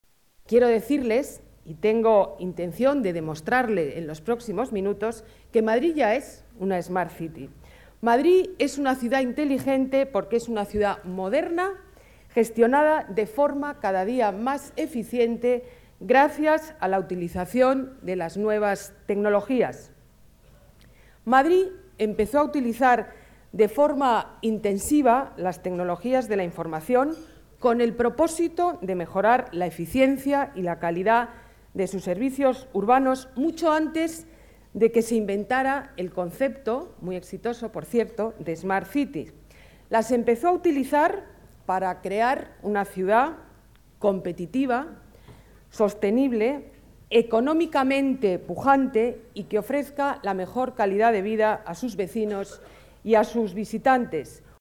Ana Botella interviene en la Tribuna Smart City, Foro de la Nueva Ciudad, organizado por Nueva Economía Fórum
Nueva ventana:Declaraciones alcaldesa de Madrid, Ana Botella: desayuno Nueva Economía, significado smart city